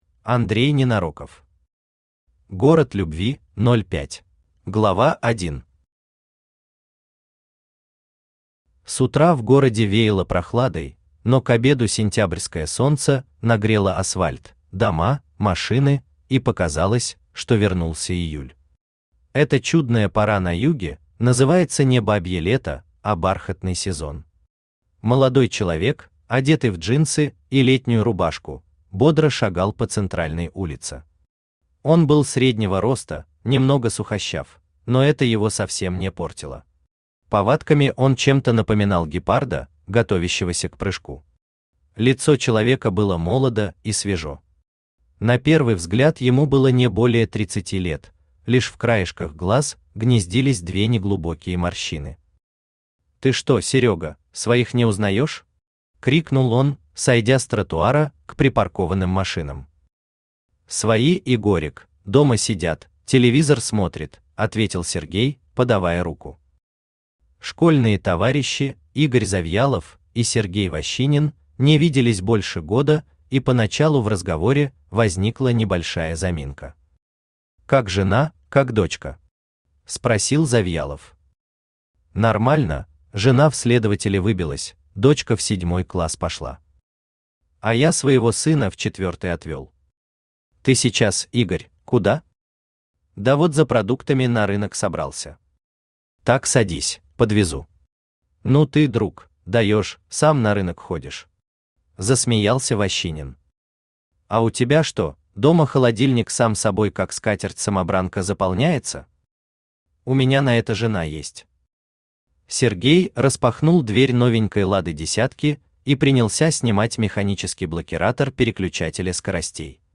Аудиокнига Город любви 05 | Библиотека аудиокниг
Aудиокнига Город любви 05 Автор Андрей Юрьевич Ненароков Читает аудиокнигу Авточтец ЛитРес.